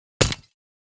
Armor Break 3.wav